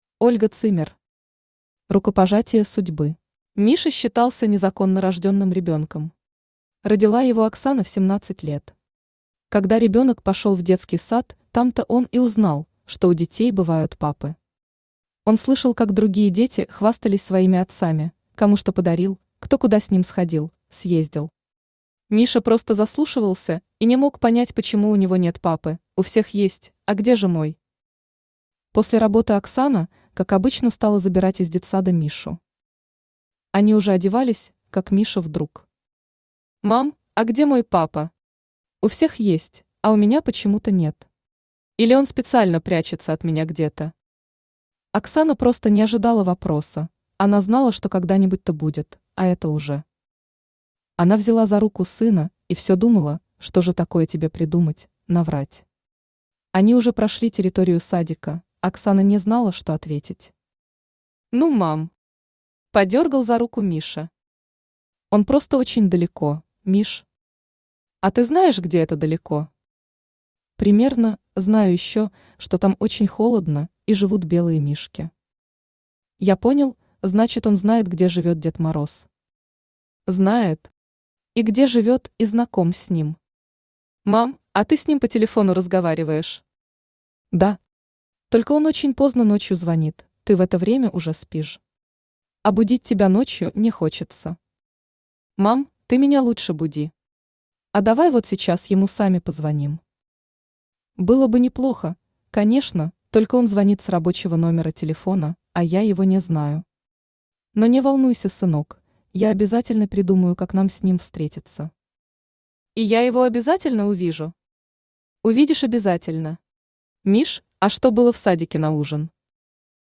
Аудиокнига Рукопожатие судьбы | Библиотека аудиокниг
Aудиокнига Рукопожатие судьбы Автор Ольга Zimmer Читает аудиокнигу Авточтец ЛитРес.